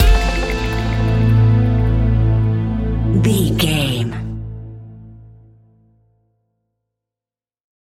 Ionian/Major
A♭
electronic
techno
trance
synthesizer
synthwave